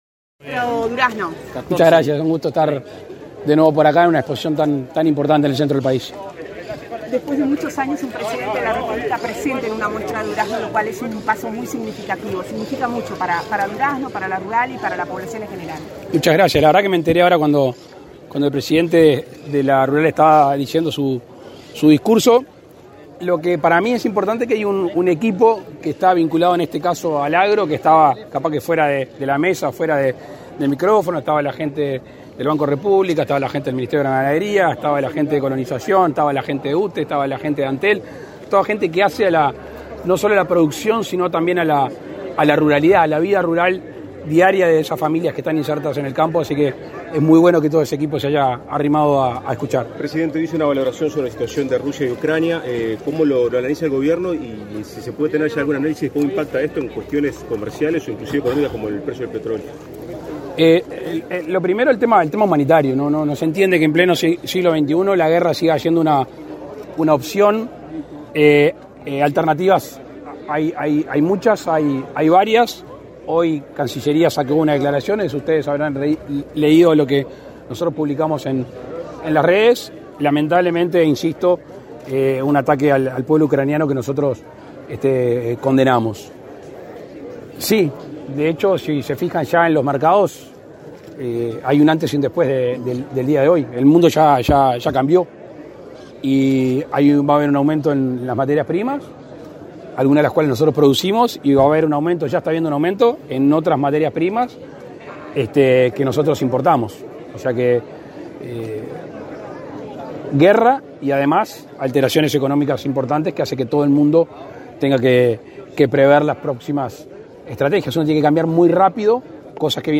Declaraciones a la prensa del presidente de la República, Luis Lacalle Pou
Declaraciones a la prensa del presidente de la República, Luis Lacalle Pou 24/02/2022 Compartir Facebook X Copiar enlace WhatsApp LinkedIn El presidente de la República, Luis Lacalle Pou, participó, este jueves 24, en la inauguración de la 108.ª Expo Durazno, que se realiza hasta el domingo 27 en la Sociedad Rural de Durazno. Tras el evento, el mandatario efectuó declaraciones a la prensa.